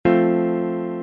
Tablature F.abcF : accord de Fa majeur
Mesure : 4/4
Tempo : 1/4=60
A la guitare, on réalise souvent les accords en plaçant la tierce à l'octave.
Forme fondamentale : tonique quinte octave tierce majeure